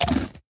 Minecraft Version Minecraft Version snapshot Latest Release | Latest Snapshot snapshot / assets / minecraft / sounds / mob / sniffer / eat1.ogg Compare With Compare With Latest Release | Latest Snapshot
eat1.ogg